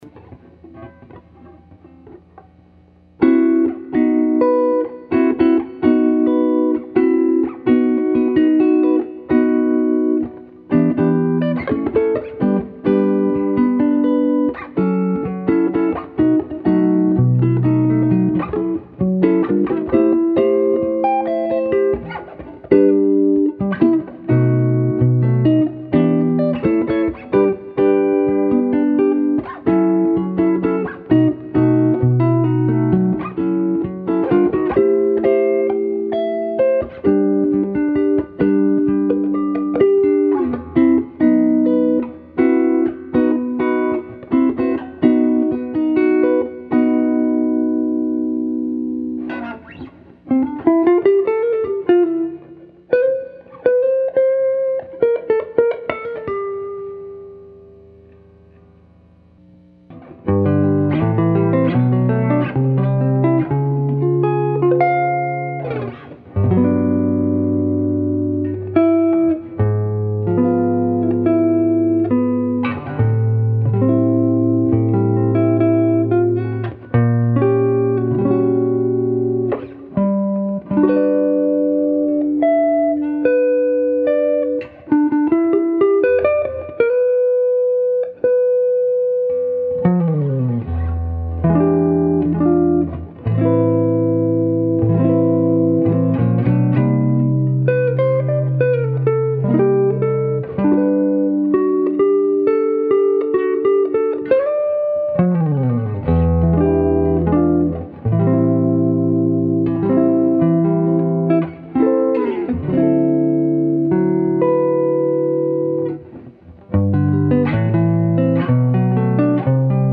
Jazz on a deluxe is sweet. I get a big warm sound from p-90, tele's and humbuckers. This clip is done with an Epiphone L5 copy with 3 p-90's. It is going straight into the deluxe and then double miced in to a Mackie board to protools.
The tone is that classic bell like jazz guitar tone...maybe a little bright but what can you do.
Sweet and Rich.
I was plugged into the lower gain (Microphone) input.
And yes, it is big and warm like Mama Cass.
jazz_deluxe_rough_mix.mp3